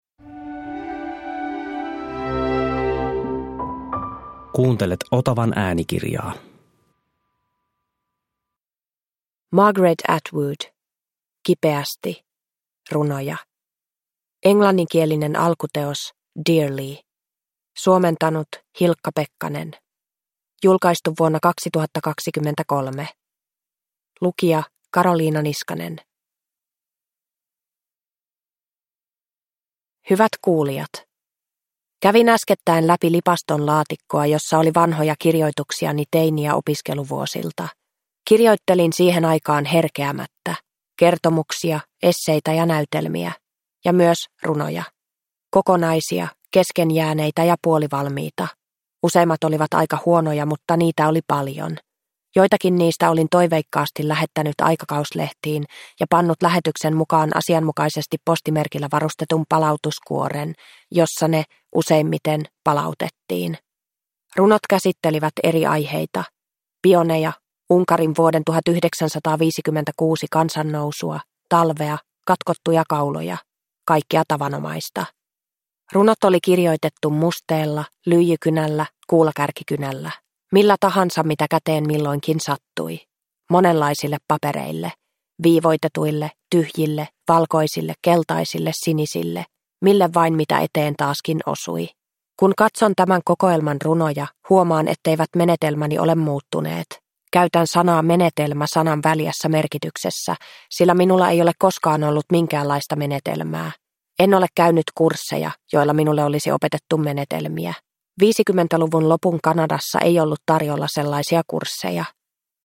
Kipeästi – Ljudbok – Laddas ner